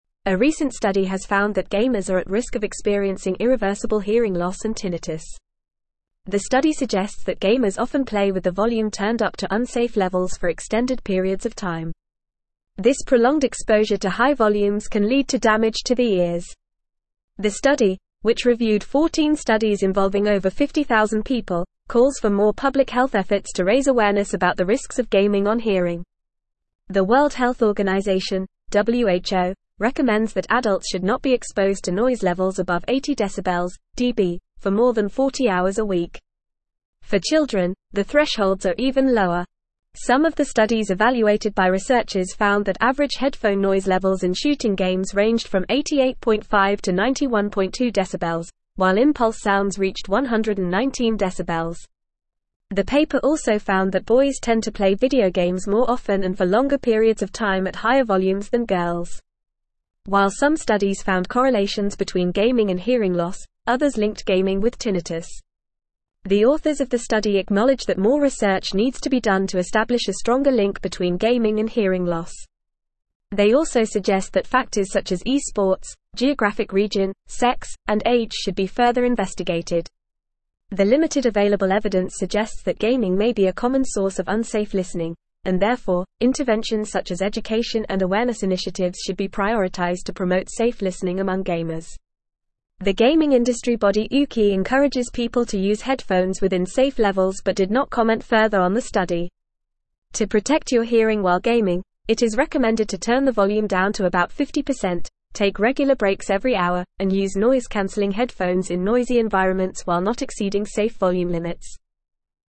Fast
English-Newsroom-Advanced-FAST-Reading-Gamers-at-Risk-of-Hearing-Loss-and-Tinnitus.mp3